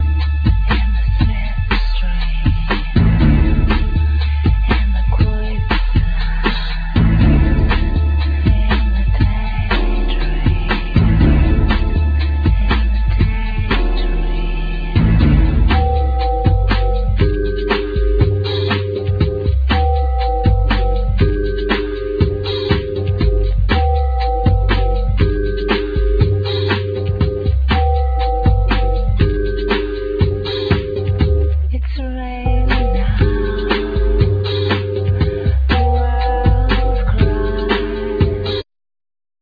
Vocals
Uillean pipes
Sax, Bass clarinet, Ba-wu flute
Acoustic guitar
Piano
Keyboards